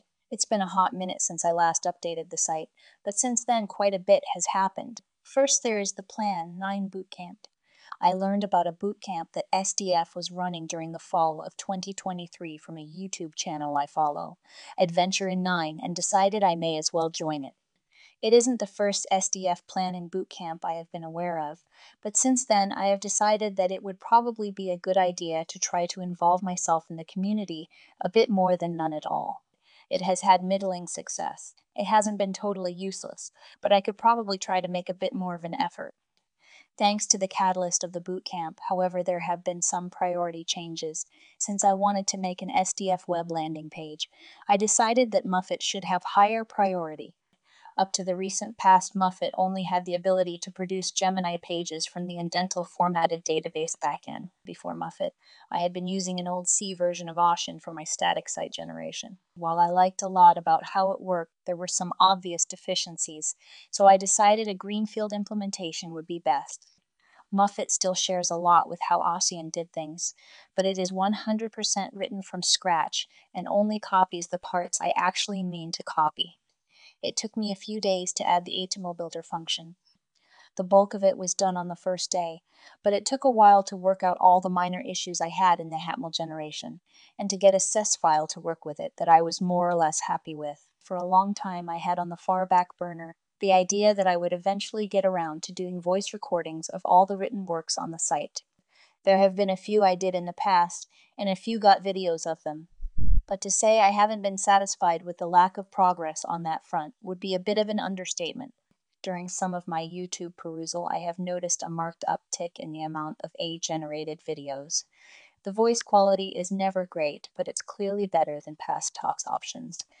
The voice quality is never "great" but it's clearly better than past TTS options, so I decided to look into how to do it locally.
So at this point I have spent the last week or so feeding the neural network all the various writings on the site and generating wav files, then manually converting the wavs into mp3s.